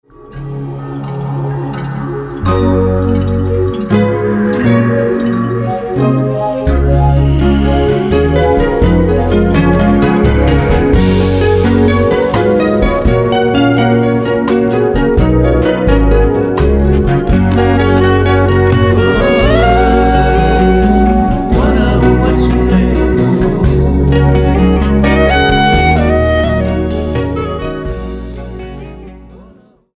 besides various harps